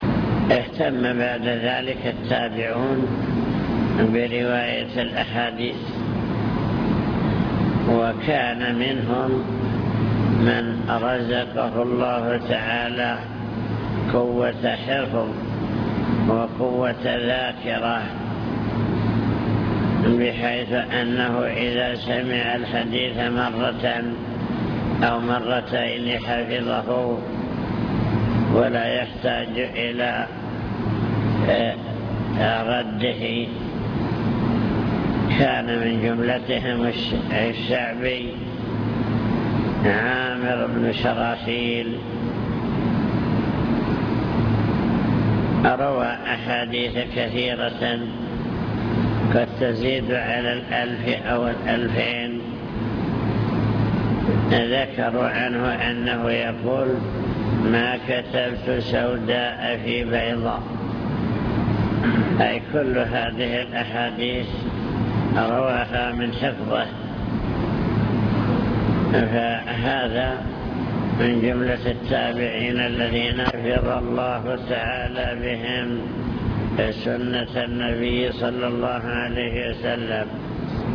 المكتبة الصوتية  تسجيلات - محاضرات ودروس  محاضرات بعنوان: عناية السلف بالحديث الشريف عناية التابعين بالحديث